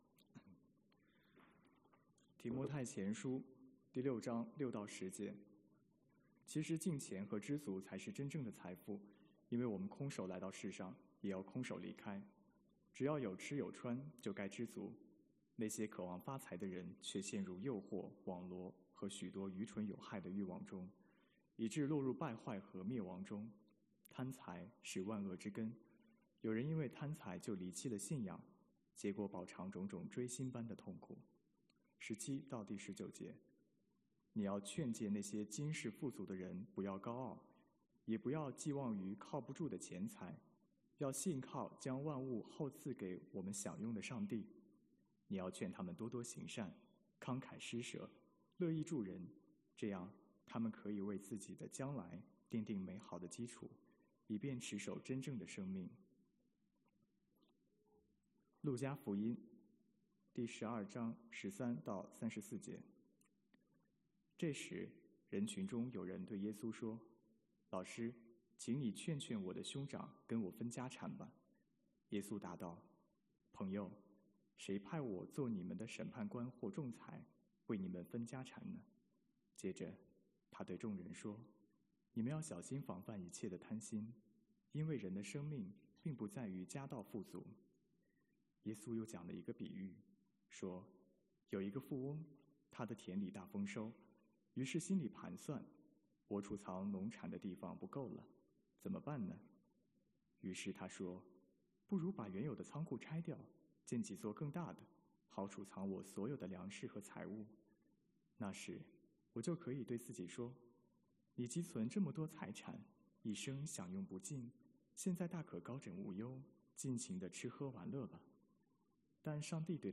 主题证道系列